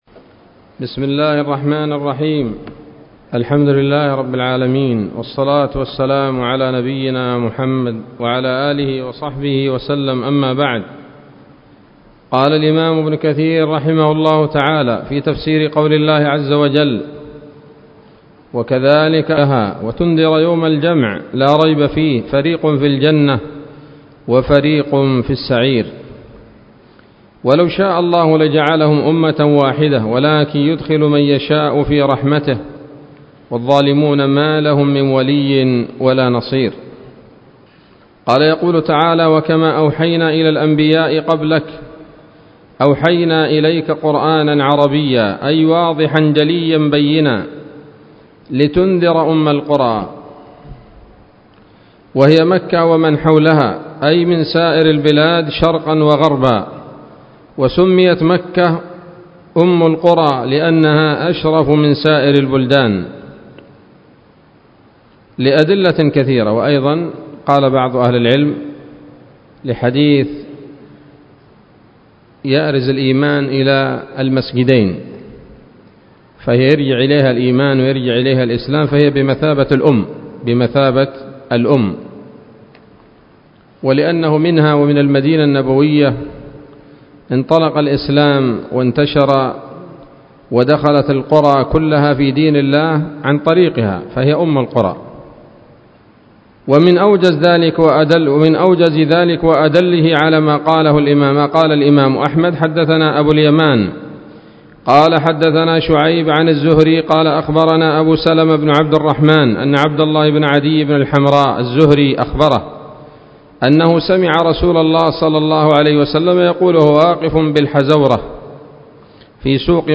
الدرس الثاني من سورة الشورى من تفسير ابن كثير رحمه الله تعالى